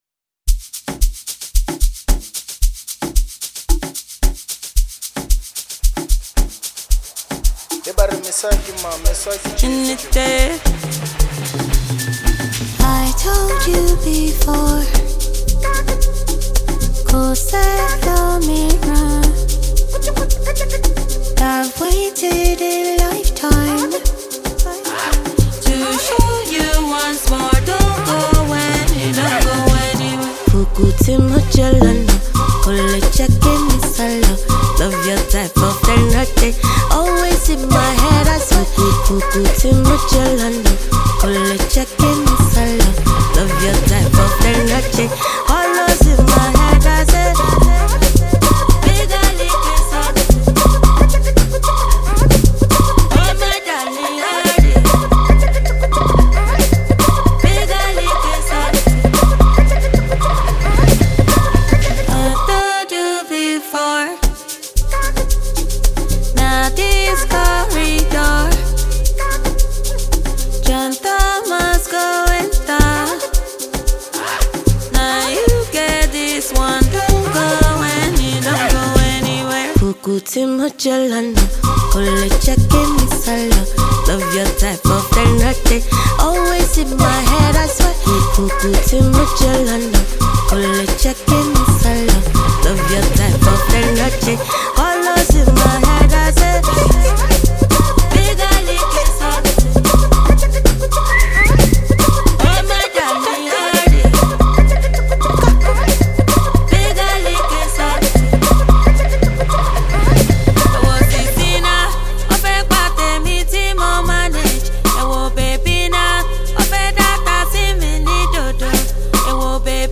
This track is groovy and beautiful!